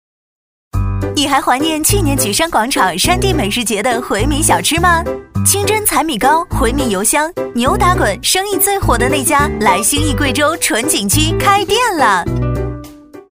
广告配音价格：30秒内100元